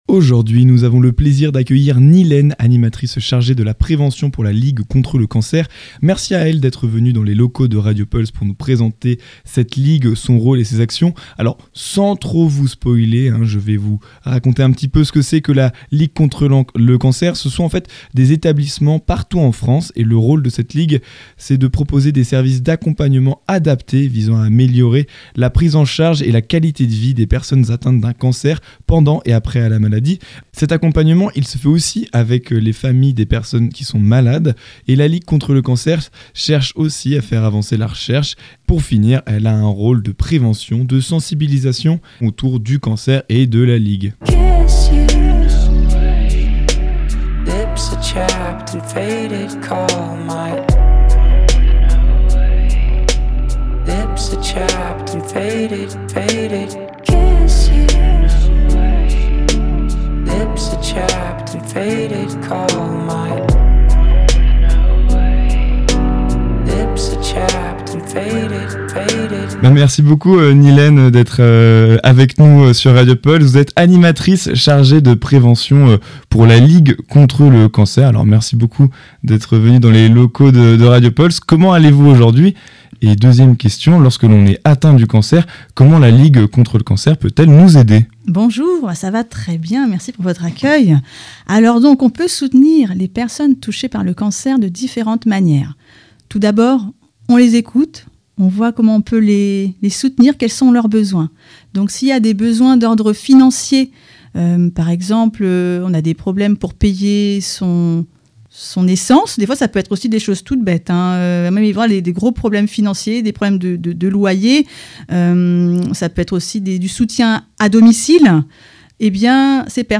Merci à elle d'être venue dans les locaux de Radio Pulse pour nous présenter cette ligue, son rôle et ses actions.